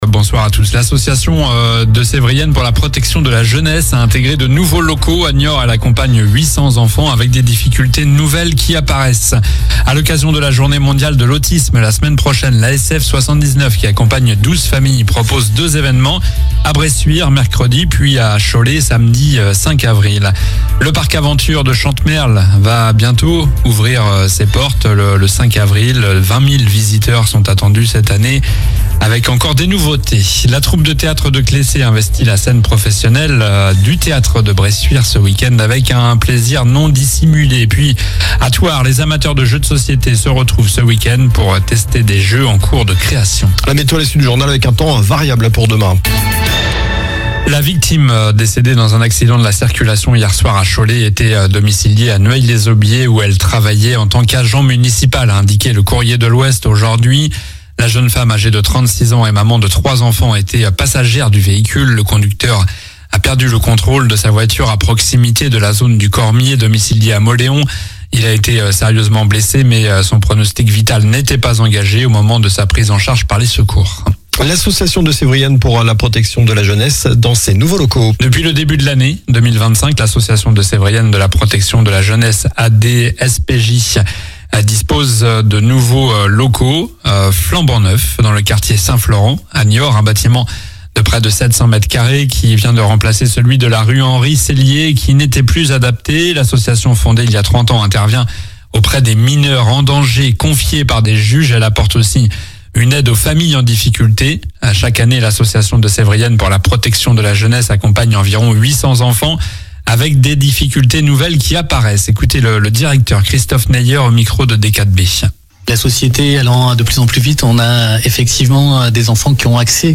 Journal du jeudi 27 mars (soir)